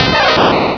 pokeemerald / sound / direct_sound_samples / cries / pikachu.aif
-Replaced the Gen. 1 to 3 cries with BW2 rips.